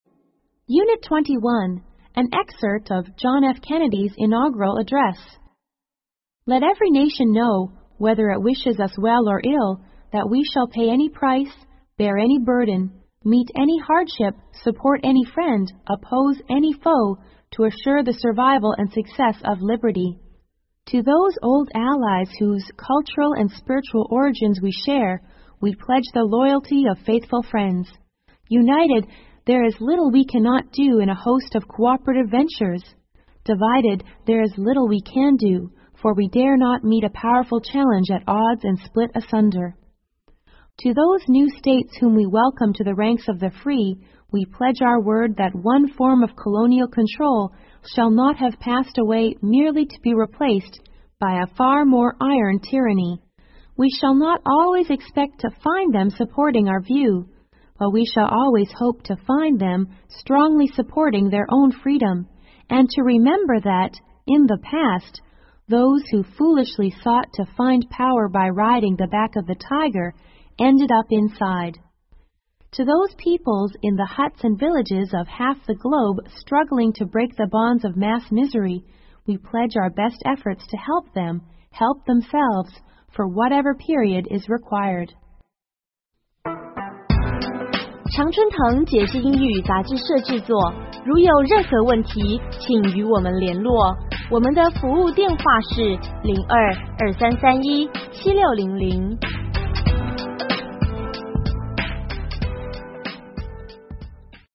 英语美文背诵 肯尼迪总统就职演说摘录 听力文件下载—在线英语听力室